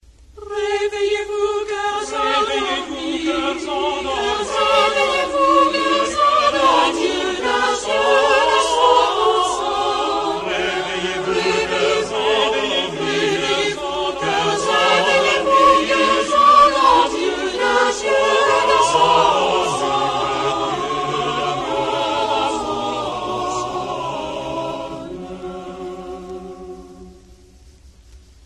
Le Chant des oiseaux est une merveilleuse fresque musicale, un concert d'oiseaux réalisé à l'aide d'onomatopées de voix qui se répondent ou se croisent. C'est également un rondeau (chanson à couplets et à refrains).
(♫) Refrain :